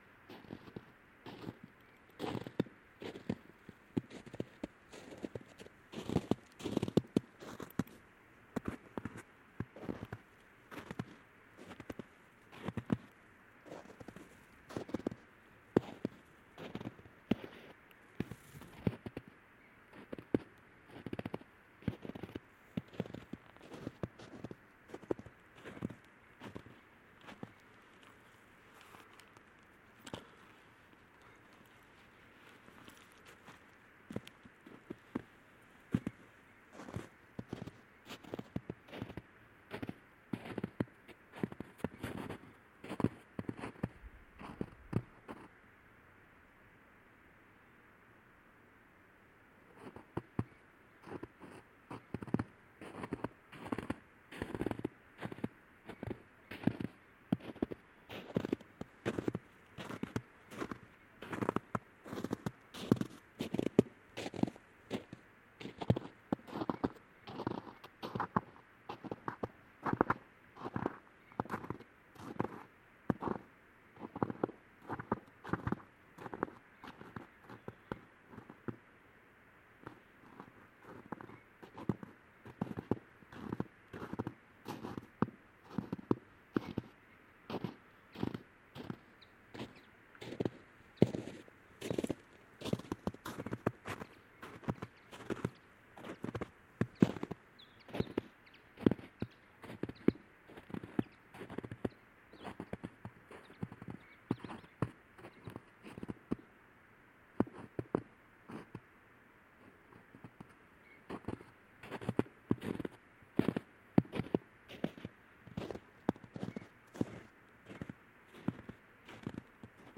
pasdanslaneige.mp3